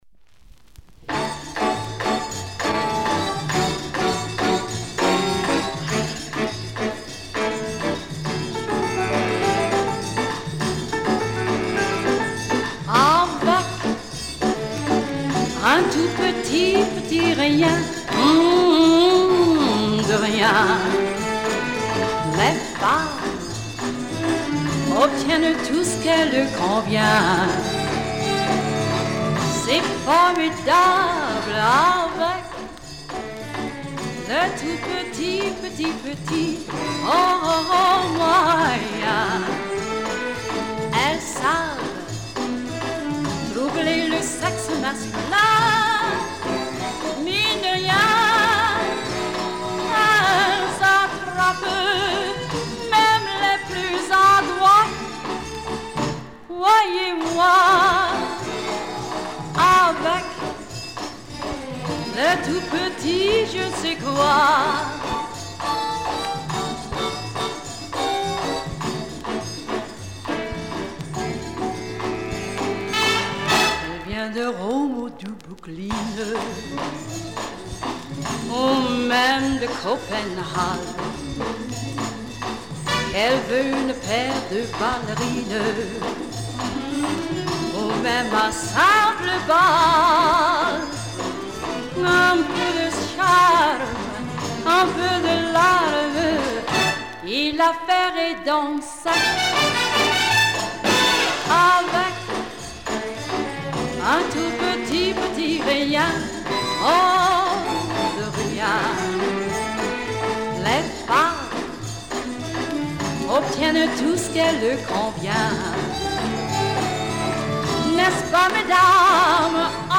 女性ボーカル
Female Vocal
立ち寄ったときに録音盤。
BIG BAND KILLER